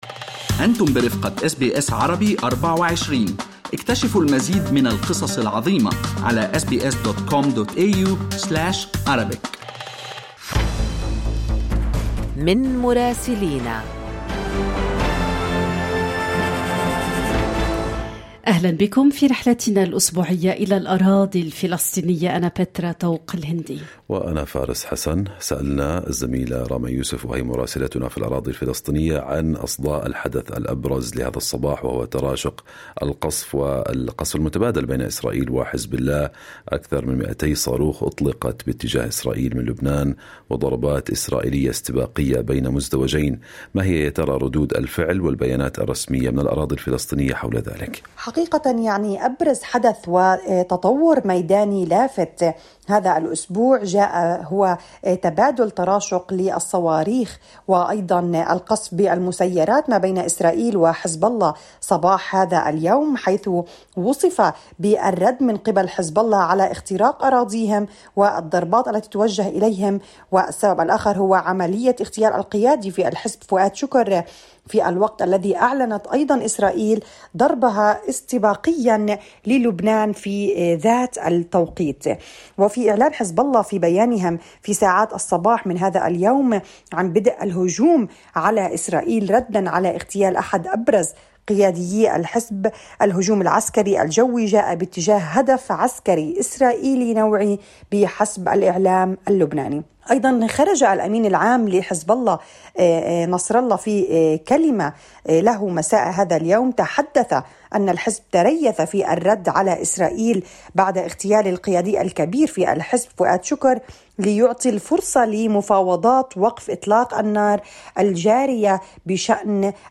من مراسلينا: أخبار الأراضي الفلسطينية في أسبوع 26/8/2024